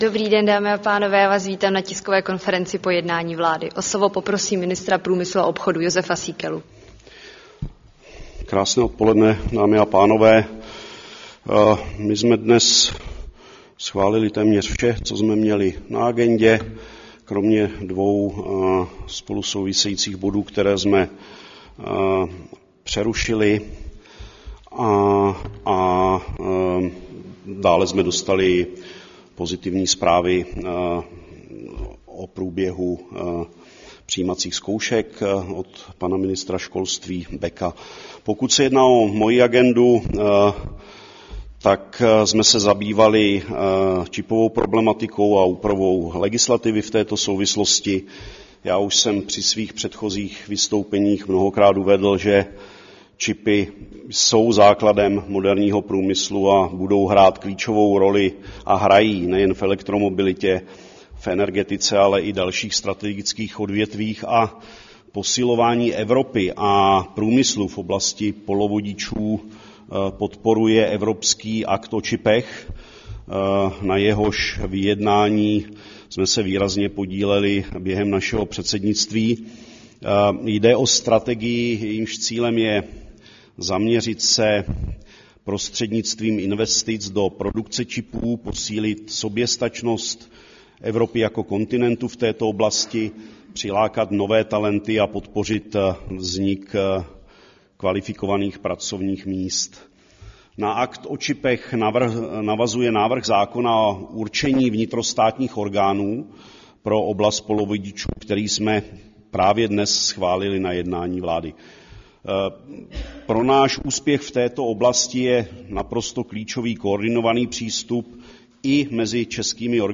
Tisková konference po jednání vlády 15. května 2024